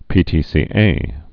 (pētē-sē-ā)